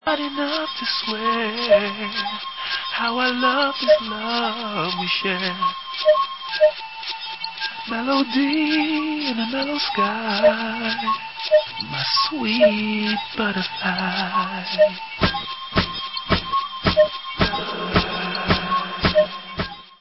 sledovat novinky v oddělení Dance/House